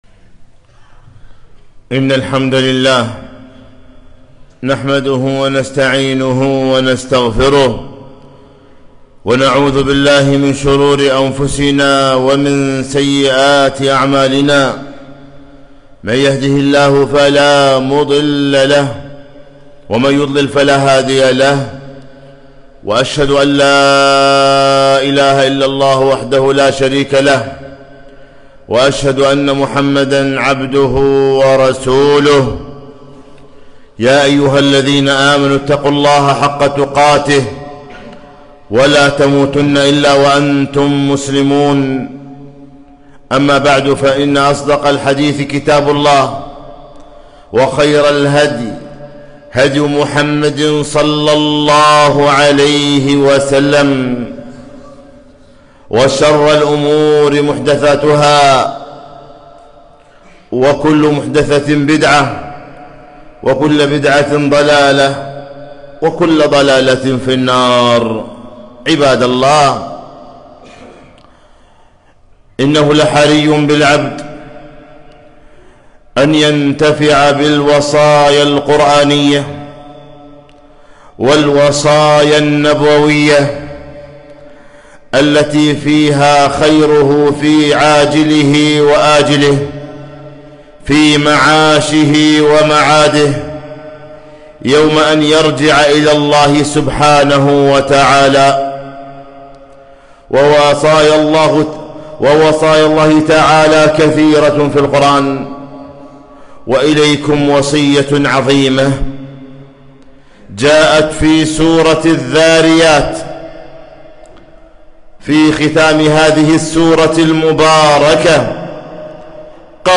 خطبة - ففروا إلى الله